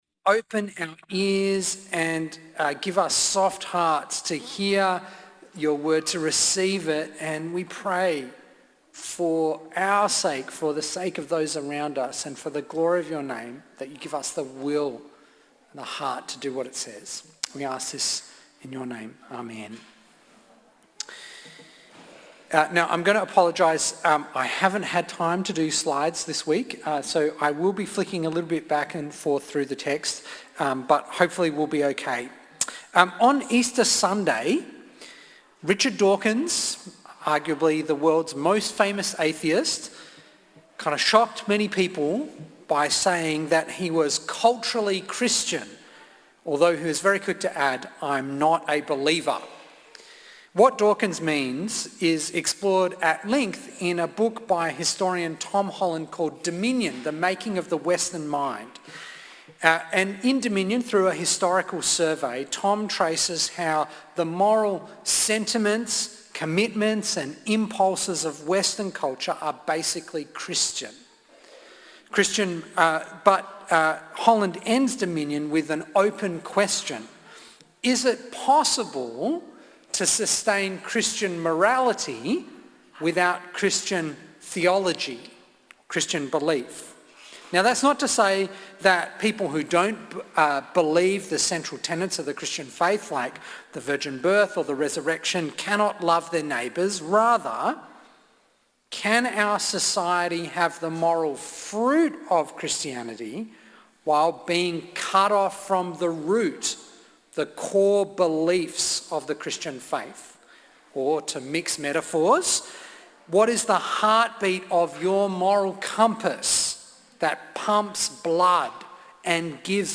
Apologies for the tech glitch at the start.